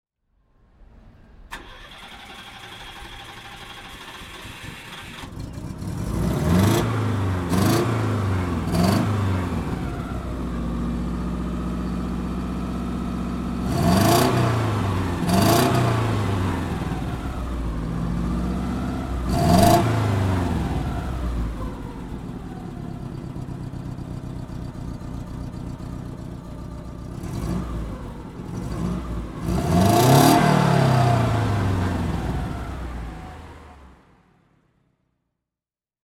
Citroën GS (1977) - Starten und Leerlauf